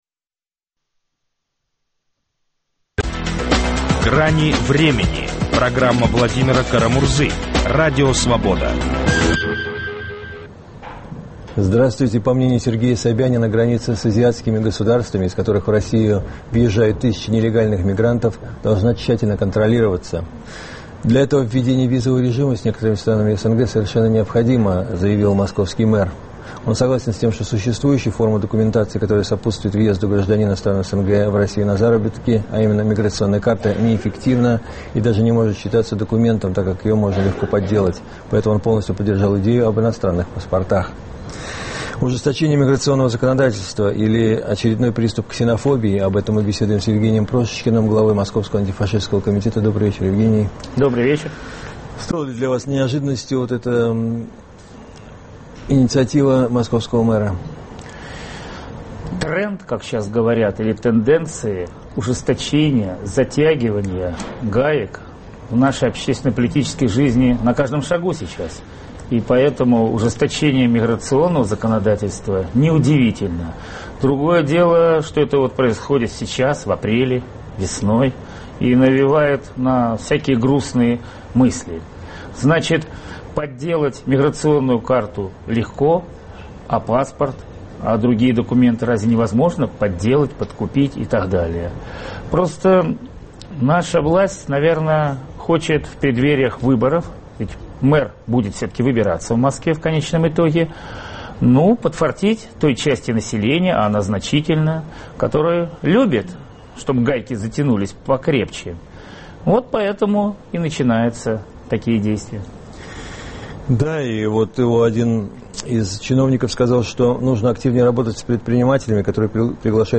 Ужесточение контроля над мигрантами или очередной приступ ксенофобии? Беседуем